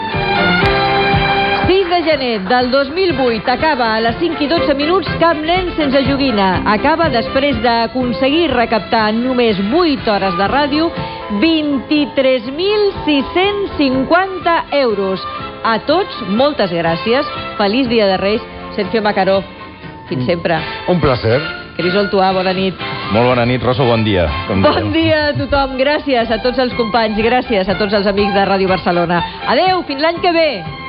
Presentador/a